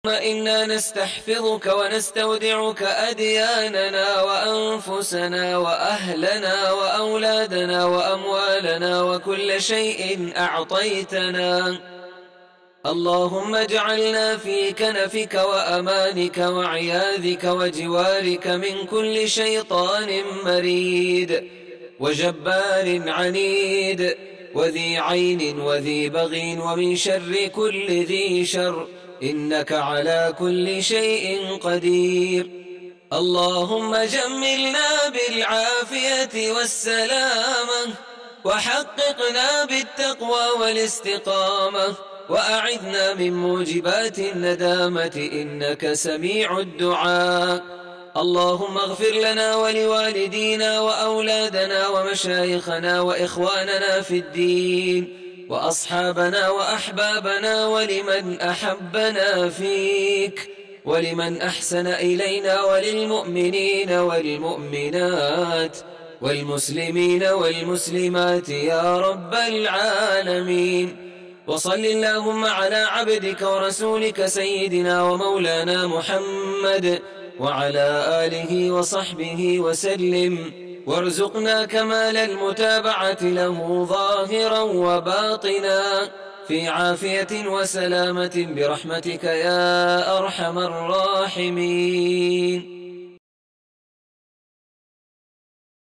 دعاء الحفظ